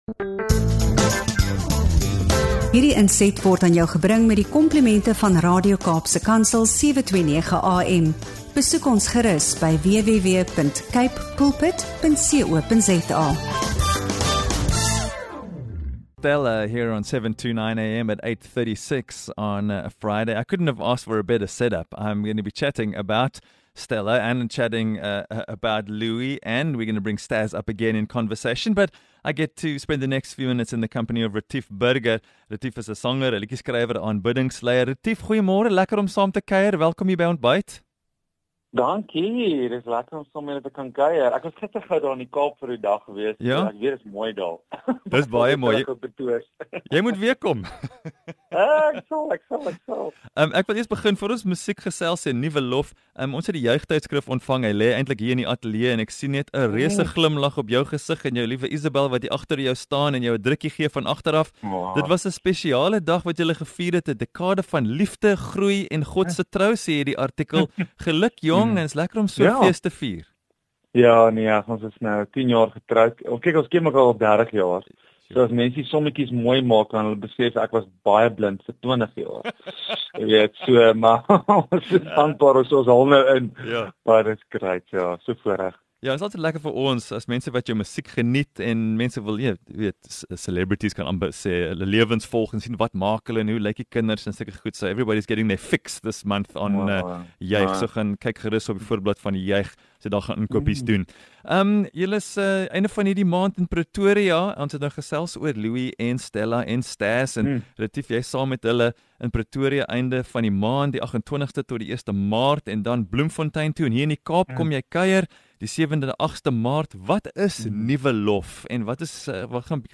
Luister in vir 'n diep gesprek oor geloof, musiek, en die toekoms van aanbidding.